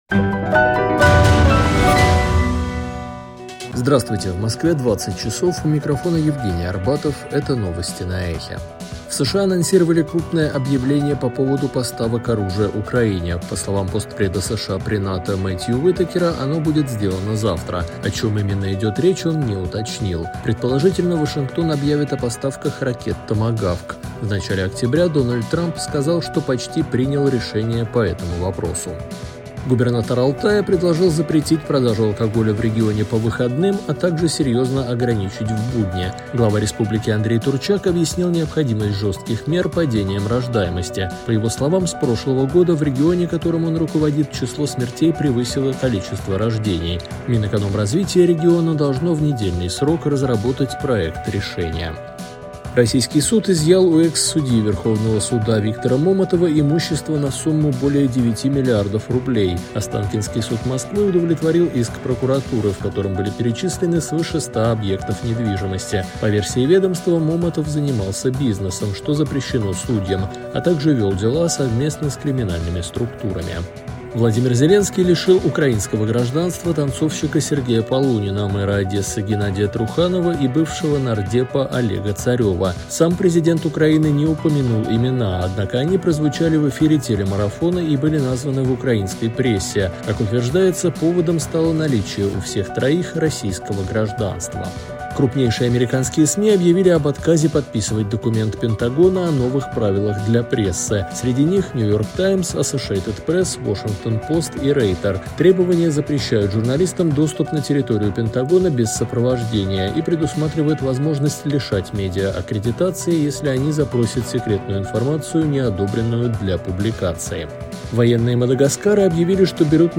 Новости 20:00